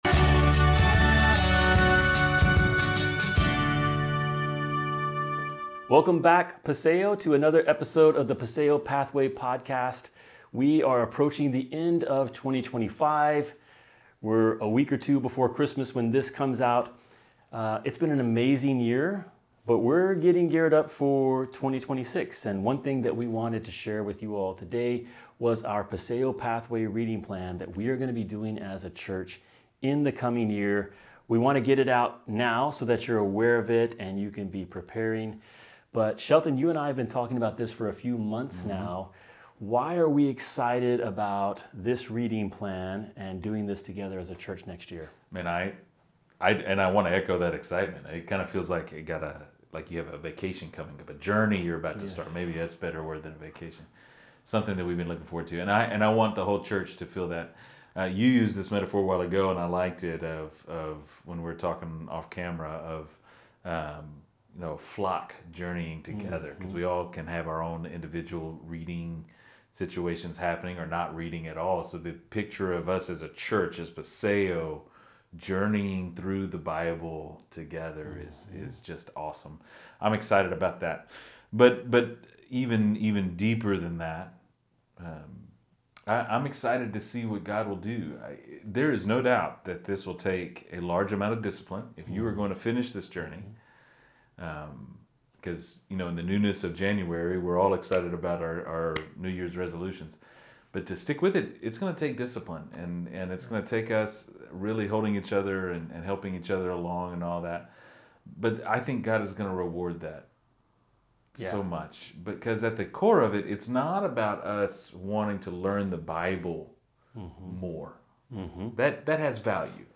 You won’t want to miss this important conversation.